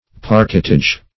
Parquetage \Par"quet*age\, n.